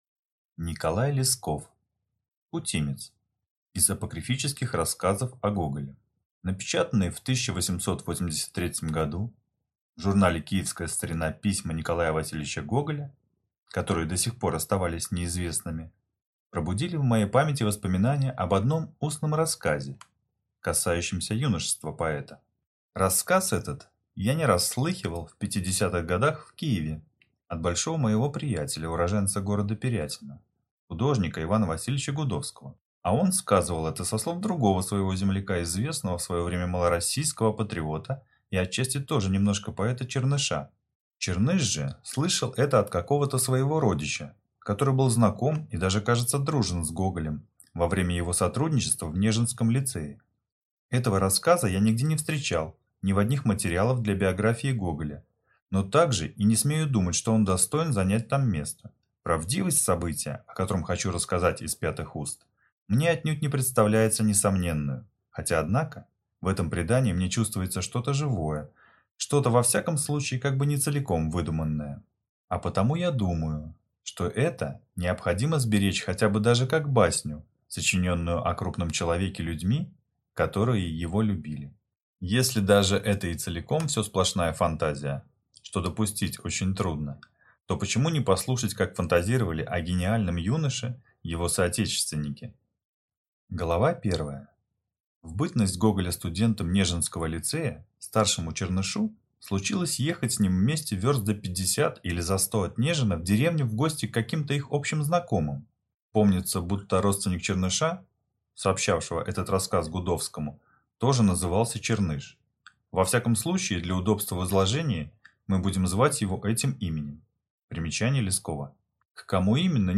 Aудиокнига Путимец